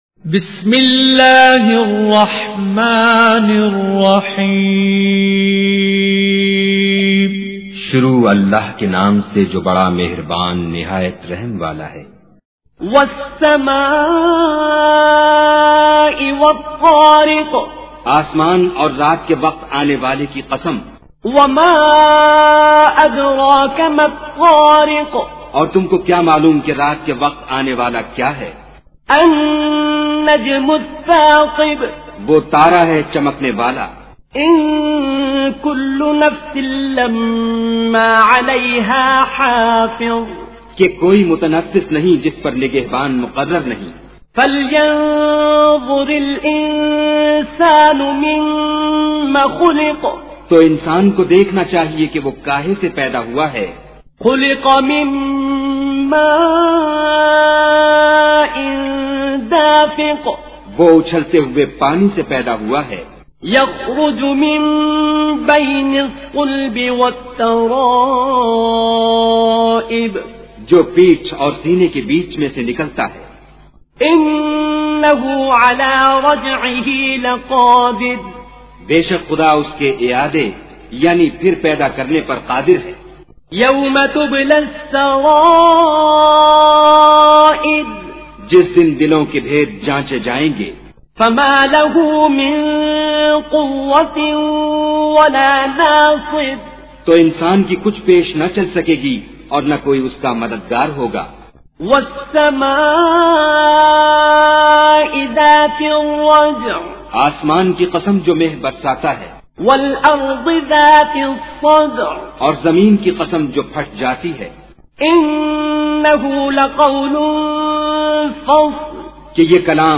تلاوت بااردو ترجمہ